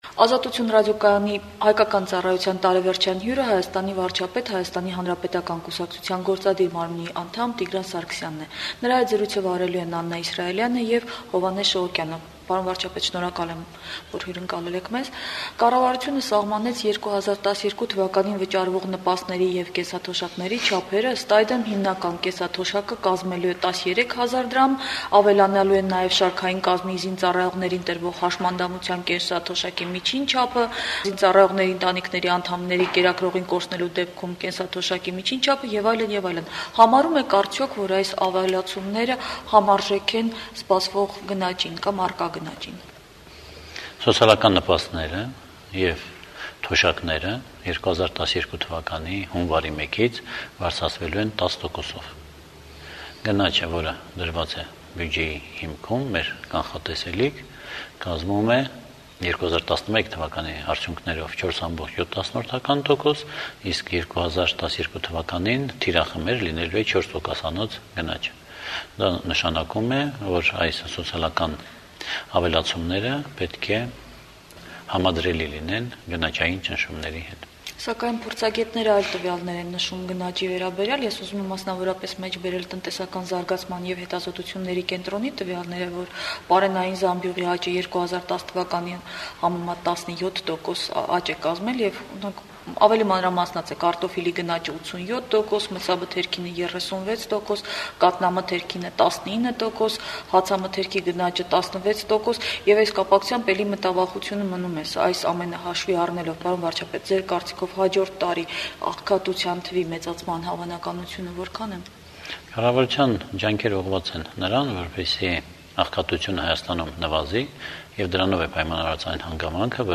«Ազատություն» ռադիոկայանի բացառիկ հարցազրույցը վարչապետի հետ։
Հարցազրույց վարչապետ Տիգրան Սարգսյան հետ